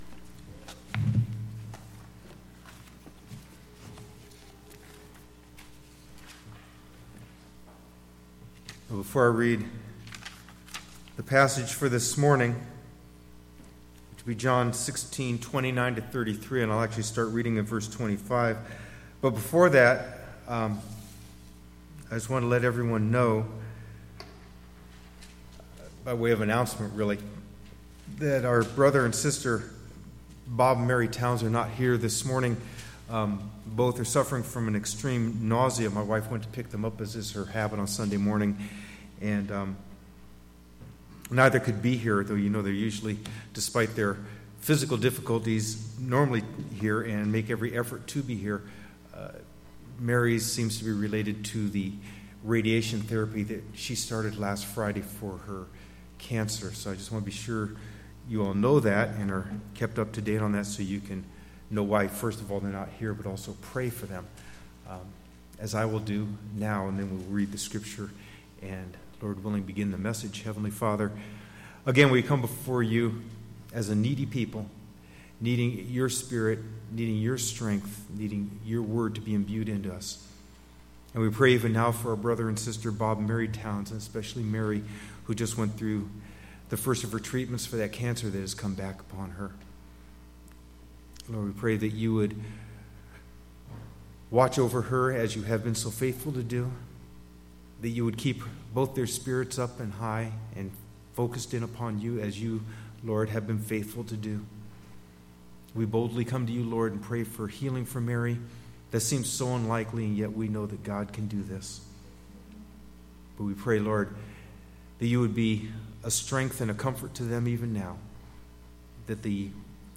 Curious about something taught in this sermon?